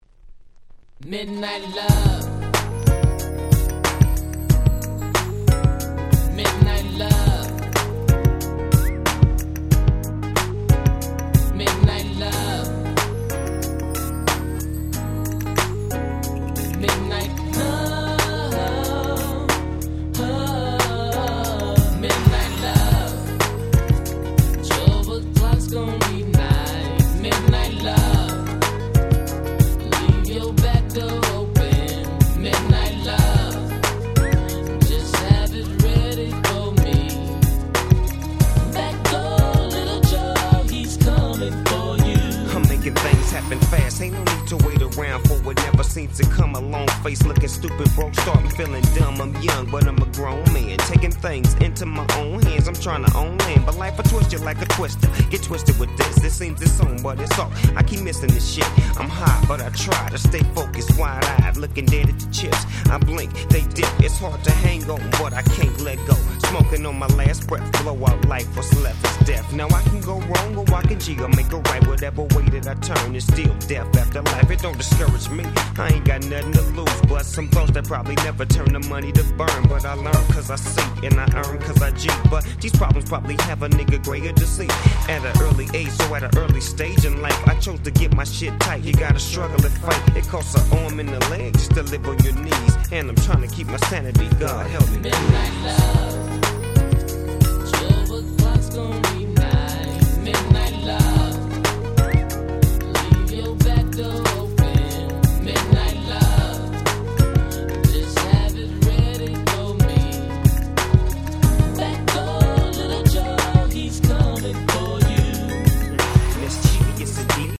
97' Nice West Coast Hip Hop !!
Mellowで切ないレイドバックな最高の1曲！！
LP Version